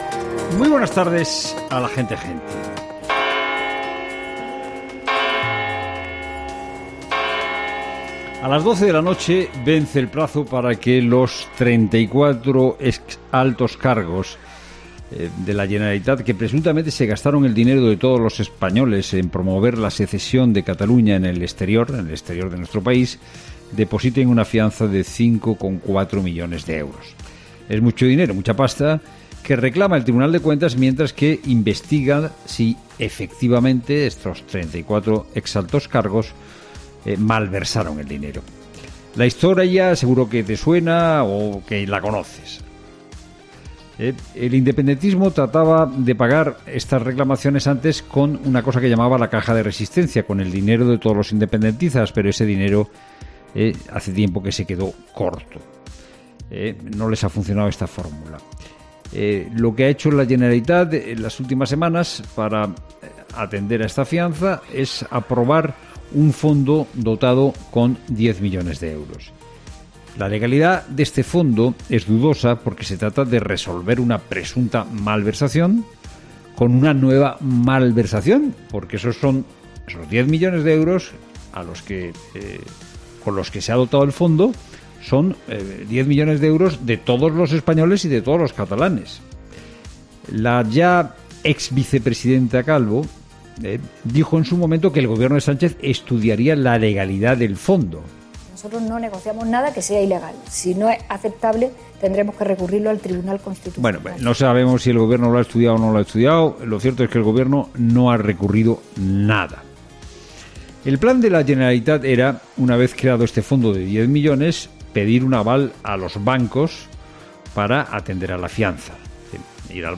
ha hablado en su monólogo sobre el Cataluña